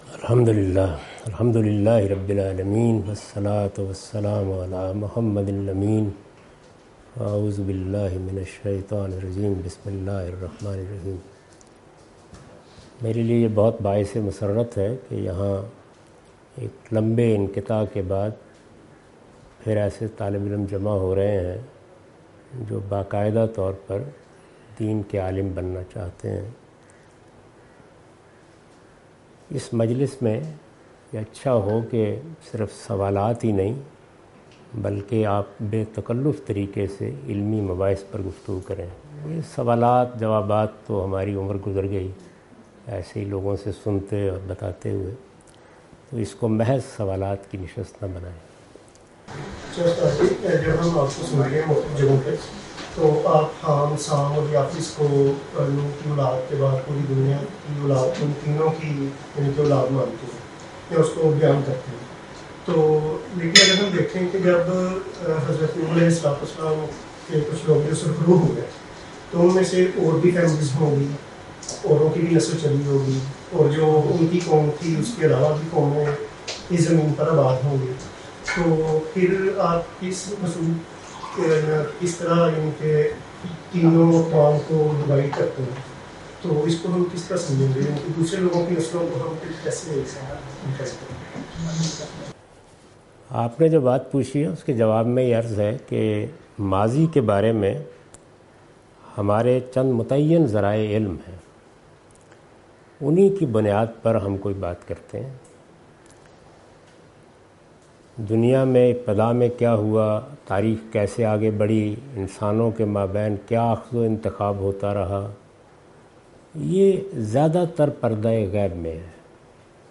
Javed Ahmad Ghamidi answer the question about different topics During his Pakistan visit in Al-Mawrid office Lahore on May 14,2022.